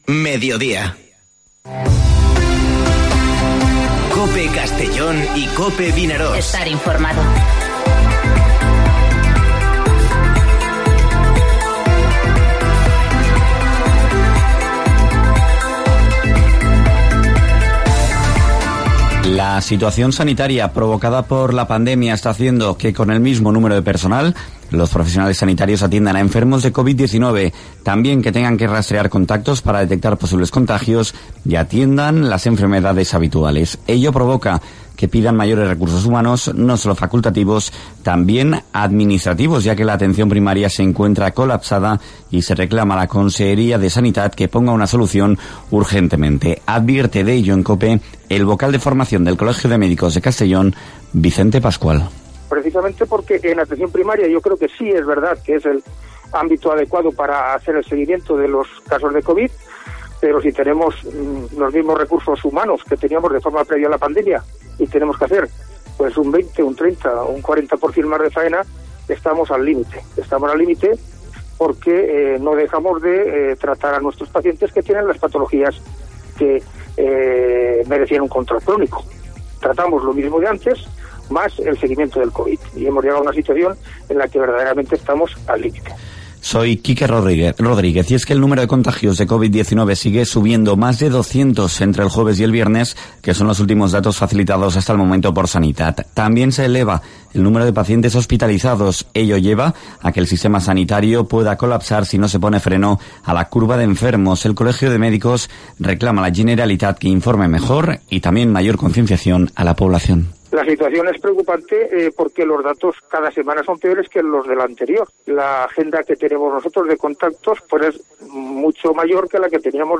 Informativo Mediodía COPE en la provincia de Castellón (14/09/2020)